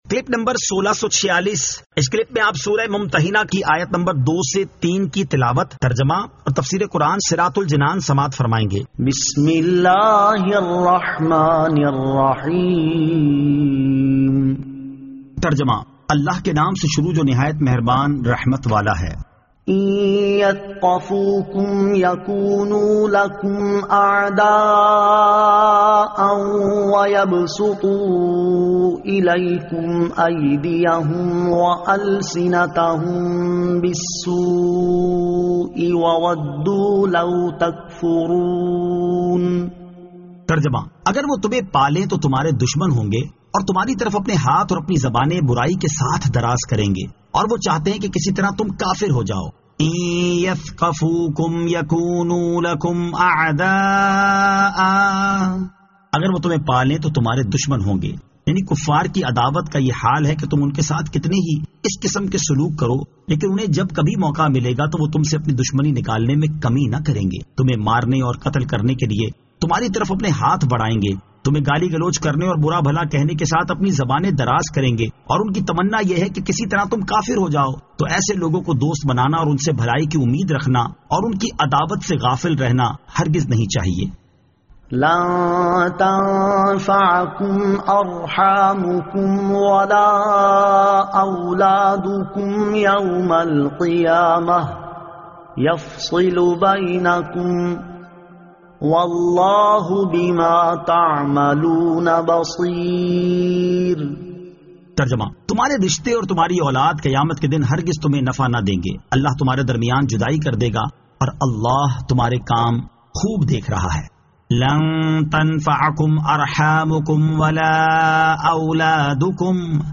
Surah Al-Mumtahinan 02 To 03 Tilawat , Tarjama , Tafseer